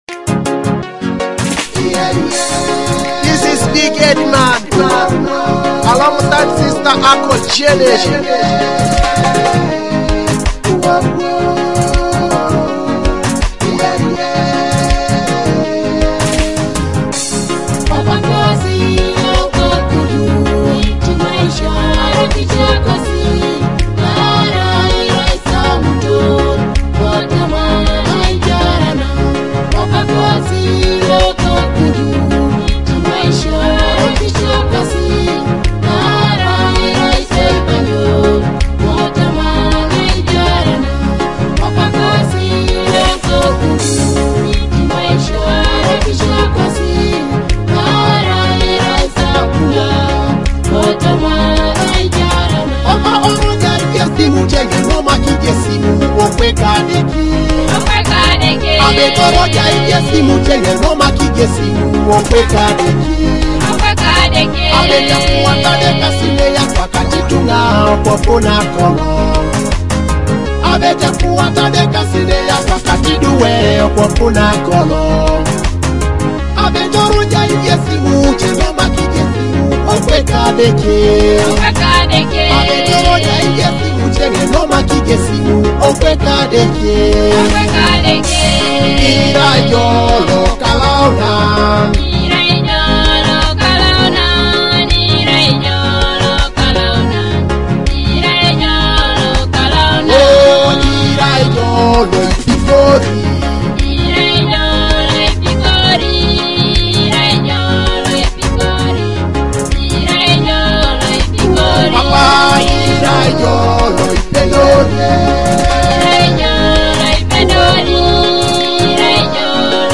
wrapped in uplifting harmonies that touch the heart.